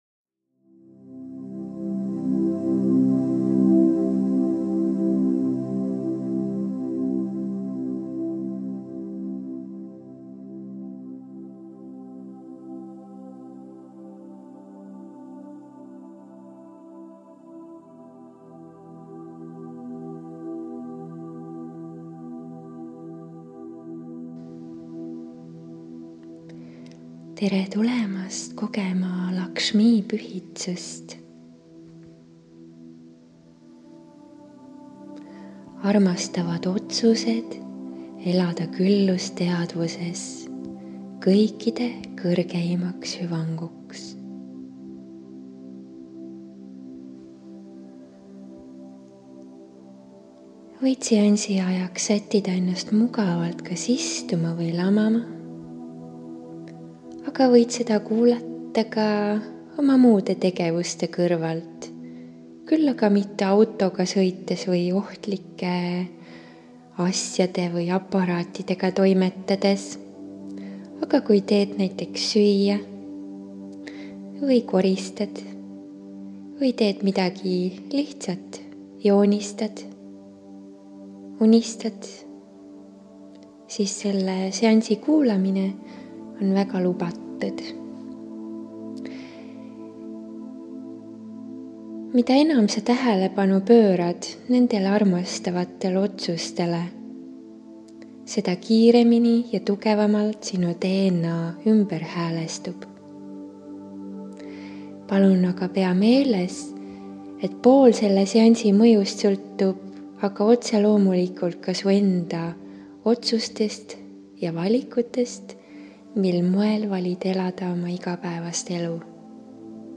SIIT LEHELT SAAD KUULATA MEDITATSIOONI